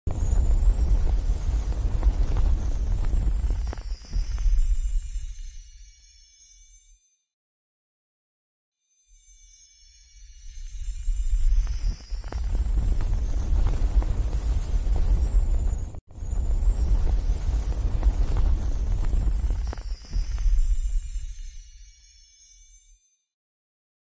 Witness AI hands sifting a sound effects free download